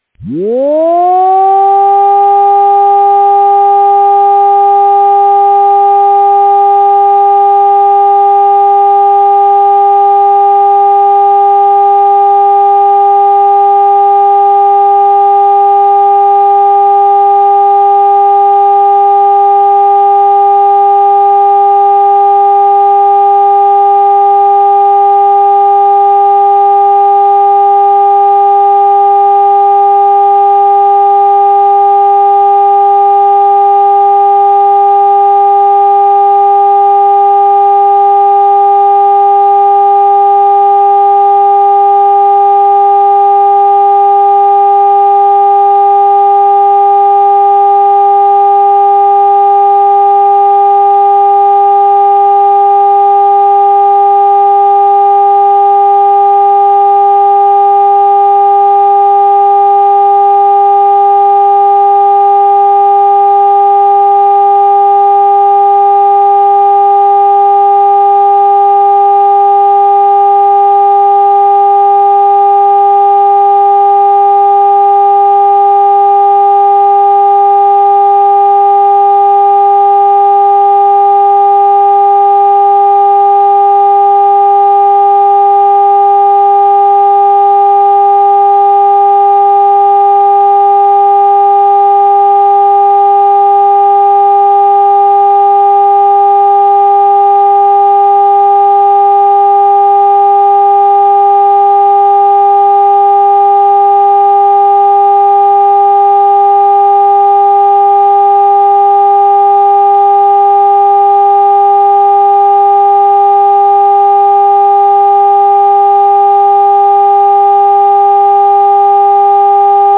Sygnały alarmowe i komunikaty ostrzegawcze
Sygnał odwołania alarmu
odwolanie_alarmu.mp3